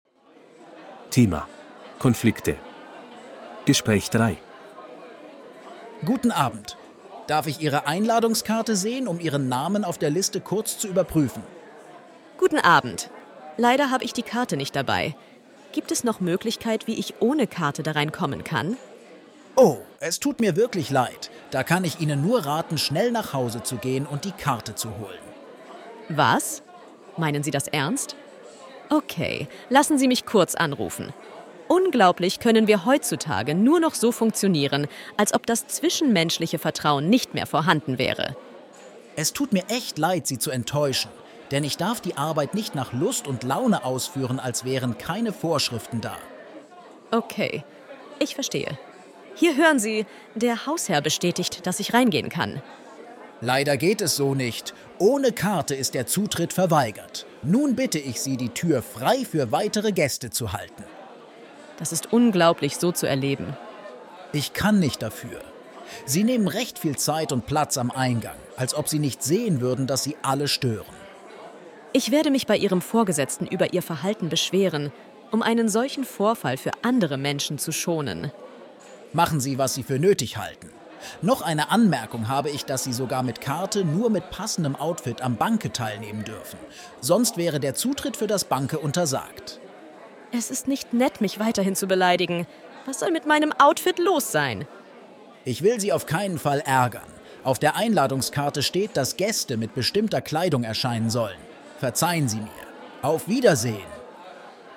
Bài nghe hội thoại 3:
B1-Registeruebung-8-Konflikte-Gespraech-3.mp3